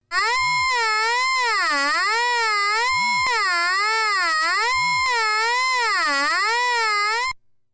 It multiplies the output of one oscillator with the output of another, which results into non-harmonic overtone structures for creating bell/gong sounds and special "metallic" effects.
And here a sample where the Sync and Ring flag are activated the same time.